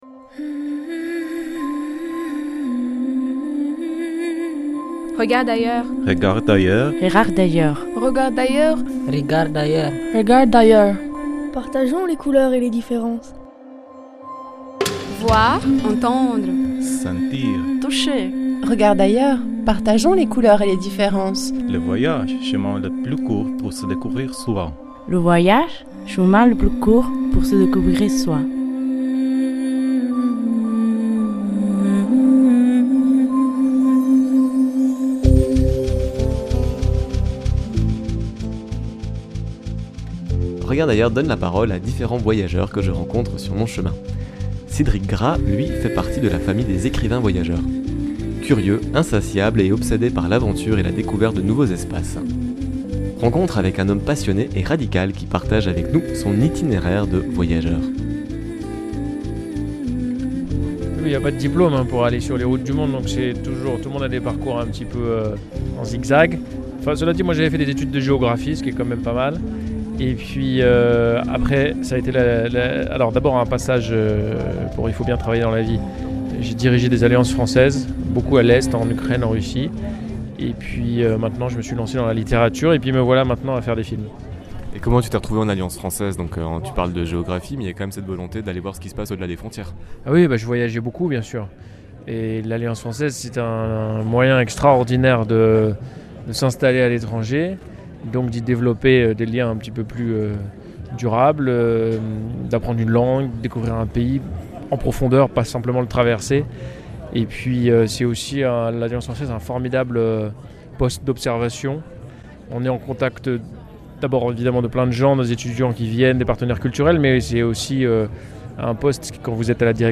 Rencontre avec un homme passionné et radical qui partage avec nous son itinéraire de voyageur.